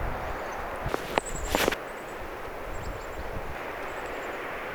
Onko töyhtötiainen?
mika_laji_onko_toyhtotiainen.mp3